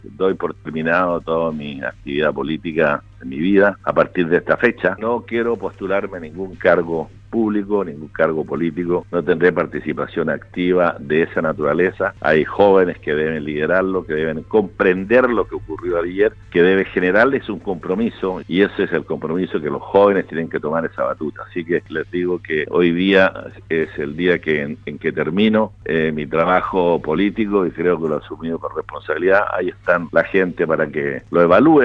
En entrevista con Radio Sago, Harry Jurgensen, repasó el resultado del plebiscito constitucional y aprovechó la oportunidad para dar a conocer que se retira de la política nacional y de cualquier cargo público.